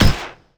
sci-fi_weapon_auto_turret_release_01.wav